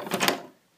door_open.ogg